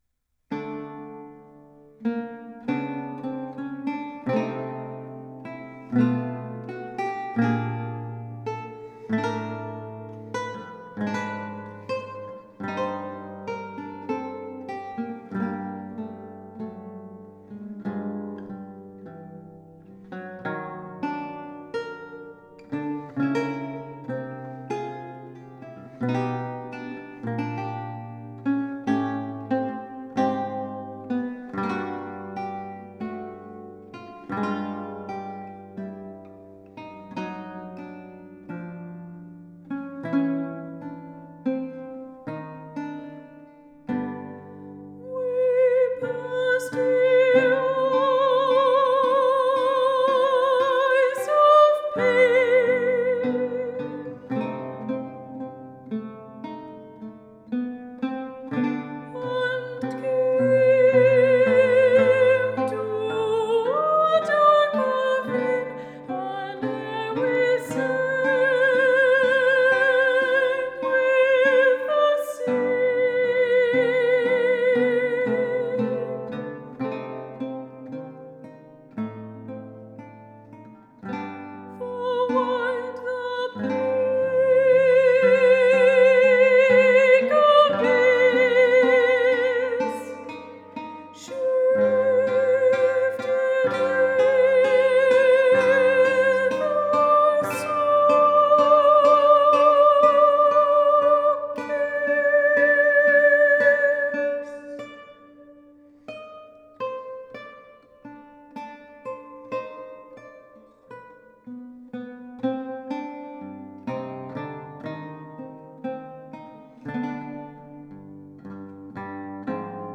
for soprano and lute